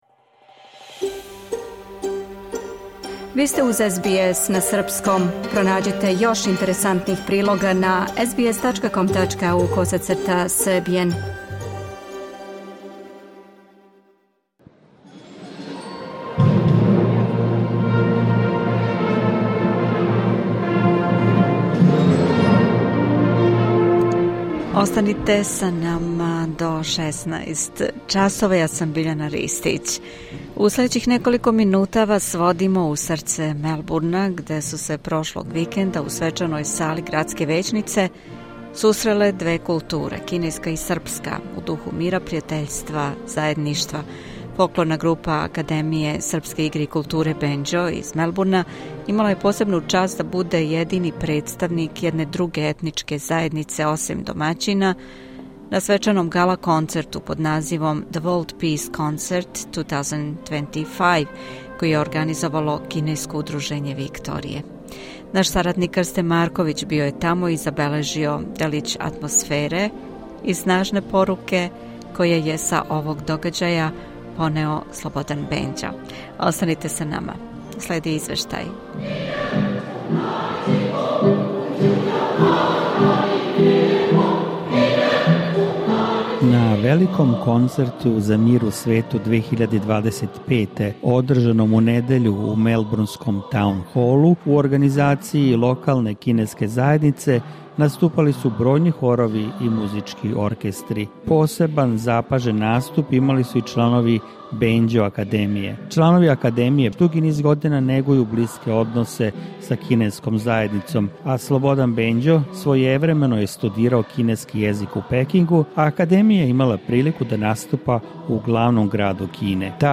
У овом прилогу водимо вас у срце Мелбурна, где су се прошлог викенда, у свечаној сали градске већнице, сусреле две културе – кинеска и српска – у духу мира, пријатељства и заједништва.